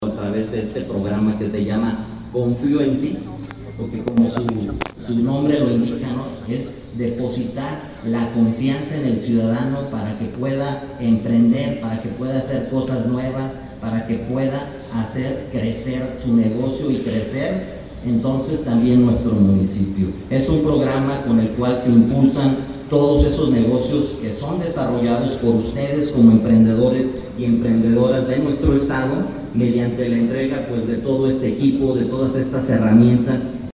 Rodolfo Gómez Cervantes, presidente interino